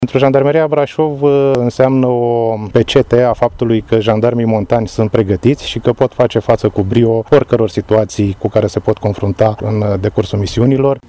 Col. Cristian Paliștan, comandantul Inspectoratul de Jandarmi Județean Brașov: